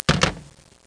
hangup.mp3